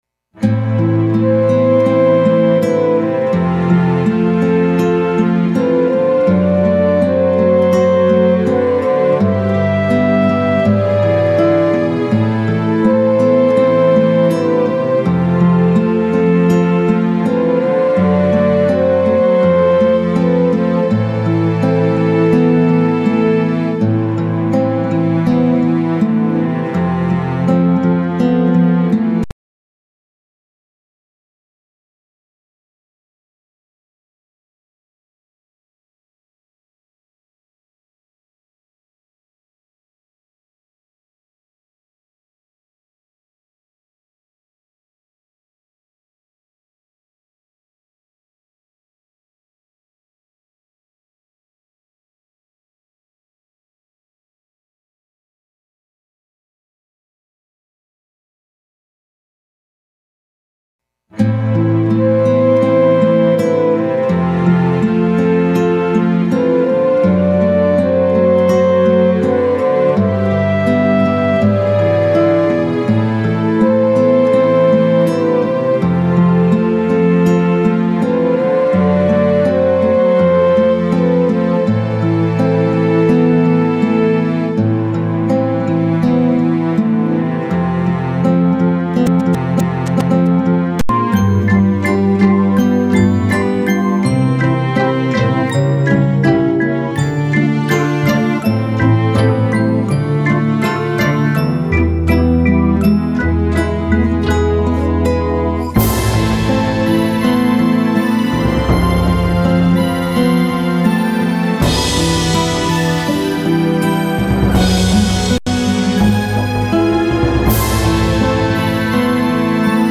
창작시노래 MR